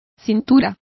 Complete with pronunciation of the translation of waists.